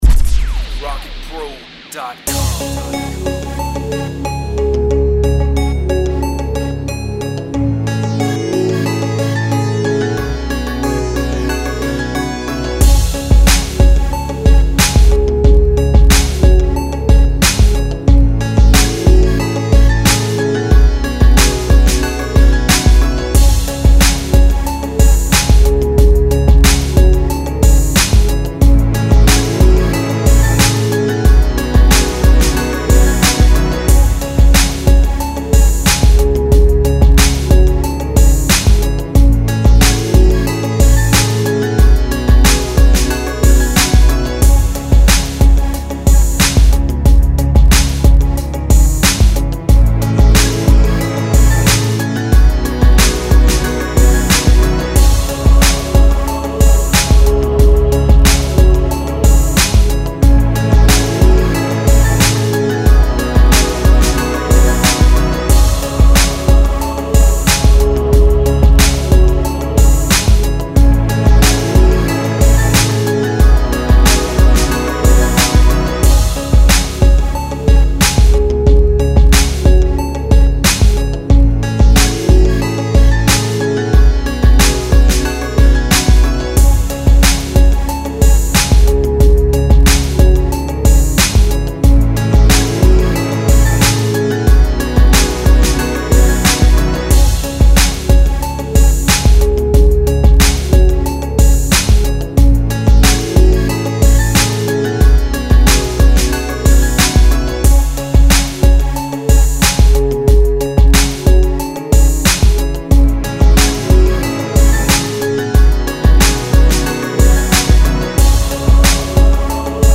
94 BPM. Nice club type beat, pizz string, flute, and synth.